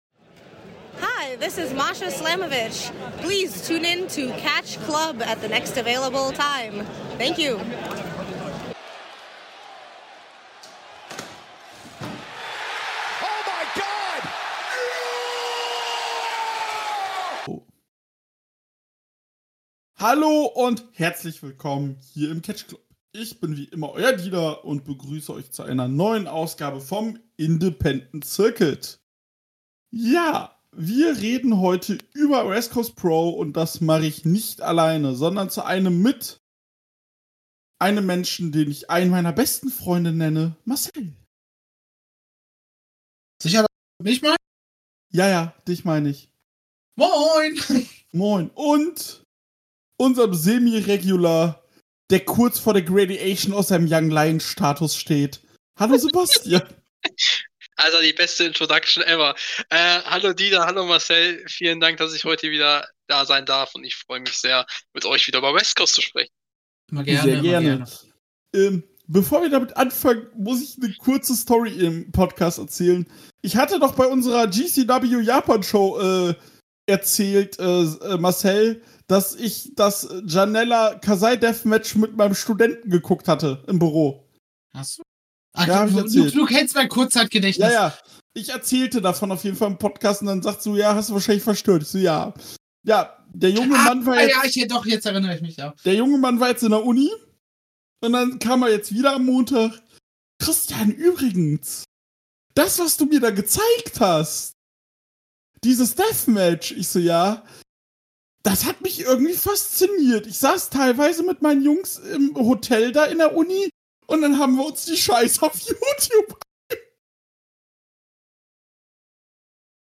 Es war Zeit für die letzte West Coast Pro Show des Jahres. Zu dritt haben wir über die Show gesprochen und haben uns die Frage gestellt, warum der World Title nicht im Main Event ist.